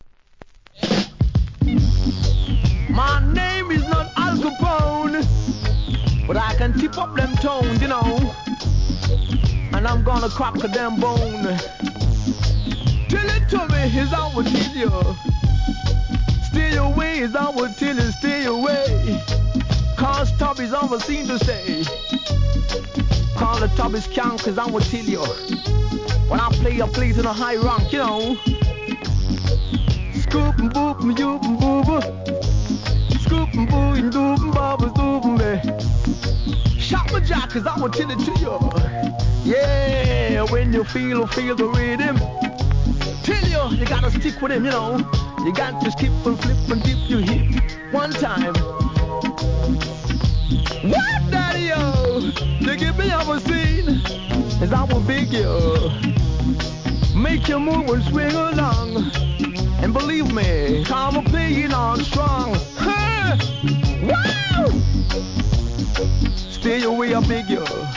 1. REGGAE
1973年、うねるシンセでHIGH RANKIN'!!